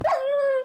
mob / wolf / death.ogg
death.ogg